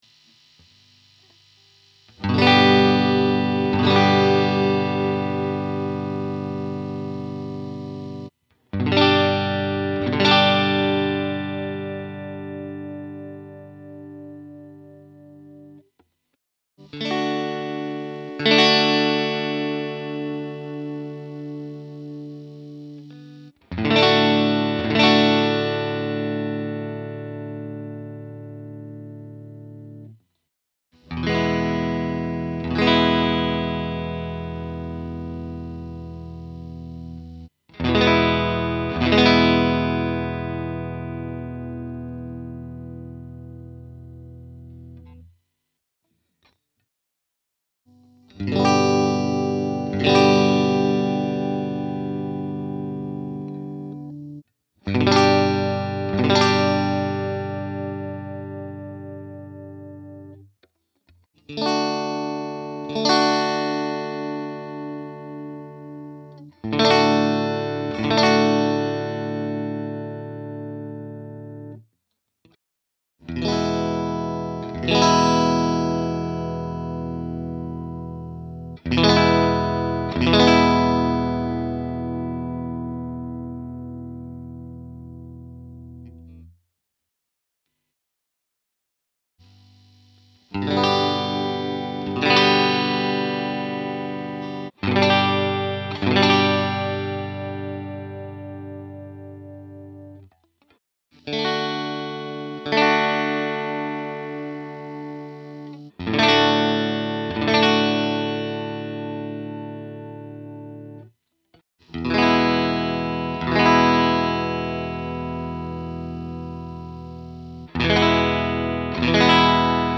Das mit dem Anschlag ist mir wohl nicht ganz gelungen, die Squier klingt viel voller, obwohl die einzelnen Doppel-Akkord-Anschläge jeweils getrennt normalisiert habe.
Bei deiner Aufnahme surrt es kräftig, auch das muss man sich wegdenken.
Mit den ersten Akkorden (G) am Halstonabnehmer und den letzten (E) mit dem Stegabnehmer, klingen die beiden Gitarren sehr gleich. :)
Das klingt teilweise wirklich ziemlich gleich:) Ich denke ich habe manchmal etwas zu zögerlich angeschlagen, im Vergleich zu dir.
Stratvergleich-American-Standard-Squier-50-CV.mp3